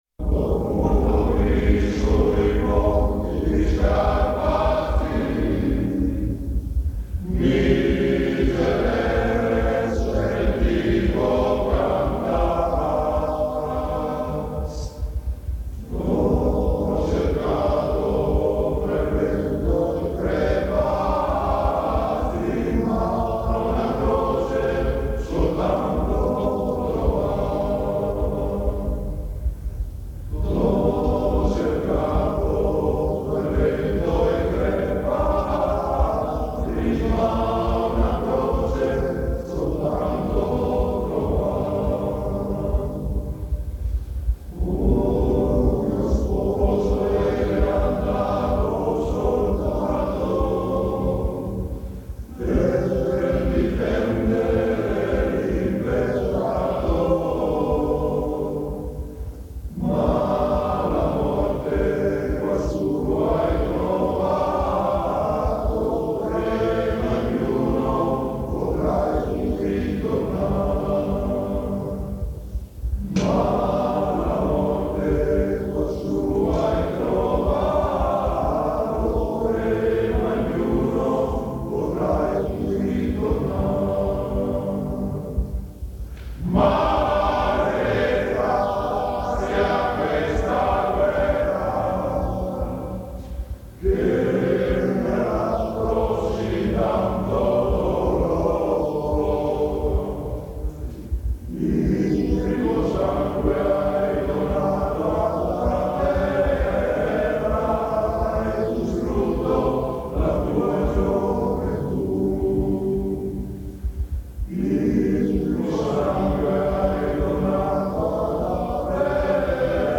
il Coro